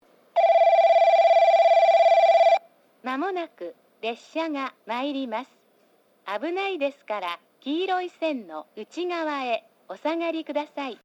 1番のりば接近放送　女声 放送は九州カンノ型Cです。放送は3回入ります。
スピーカーはJVCラインアレイですが、ホームの両端付近には「UNI-PEXクリアホーン」もあります。